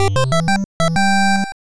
snd_boost.wav